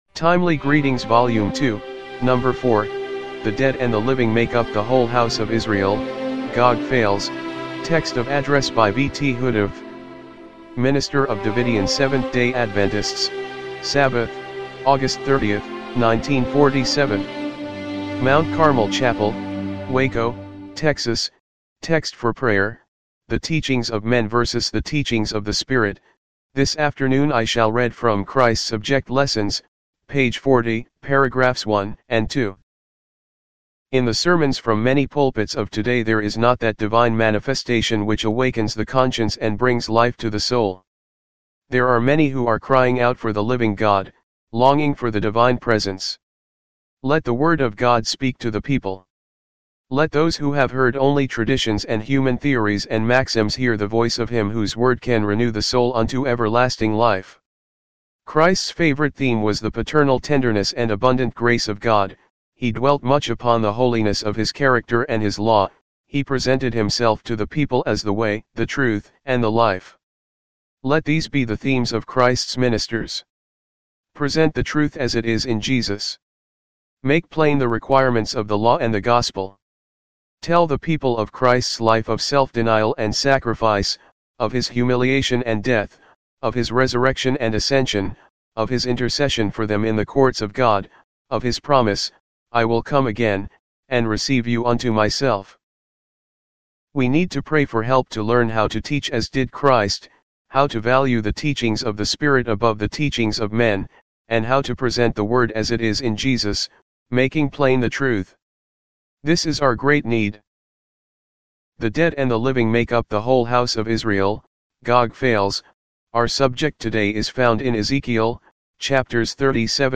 1947 MT. CARMEL CHAPEL WACO, TEXAS